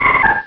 pokeemerald / sound / direct_sound_samples / cries / ralts.aif